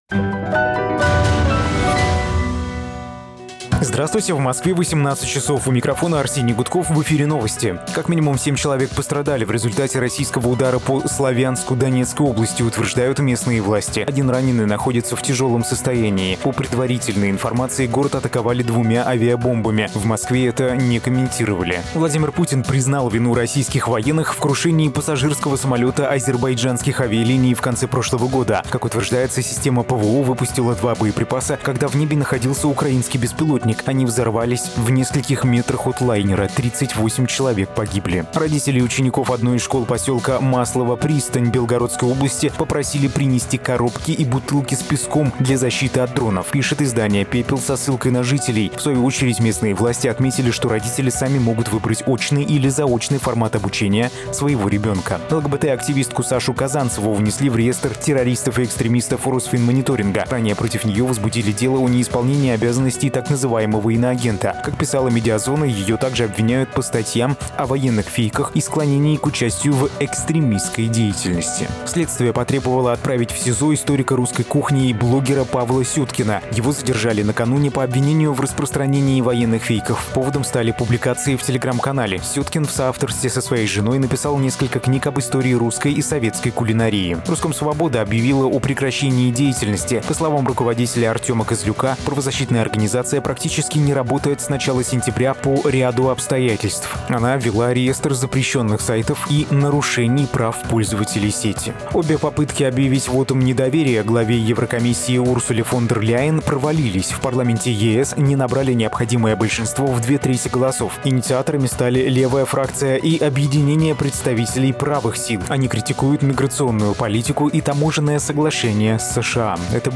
Новости 18:00